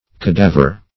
Cadaver \Ca*da"ver\ (k[.a]*d[a^]"v[~e]r; k[.a]*d[=a]"v[~e]r), n.